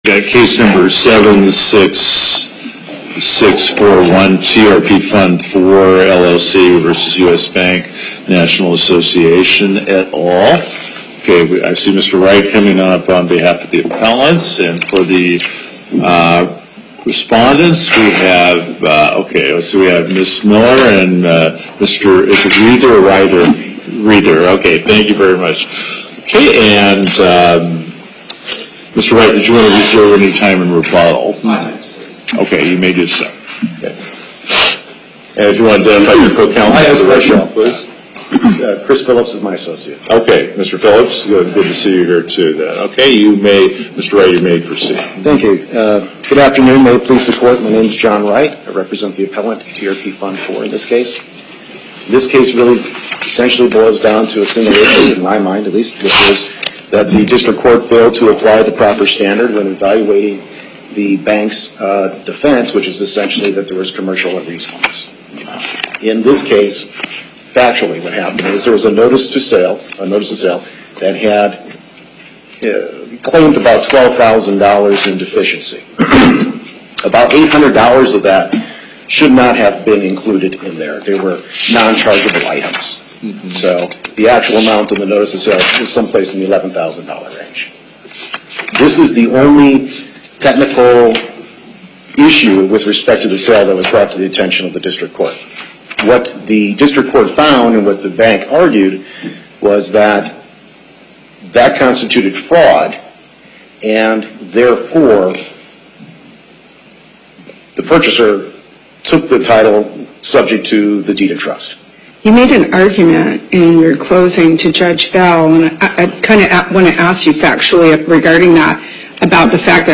1:30 P.M. Location: Las Vegas Before the Southern Nevada Panel, Justice Gibbons Presiding Appearances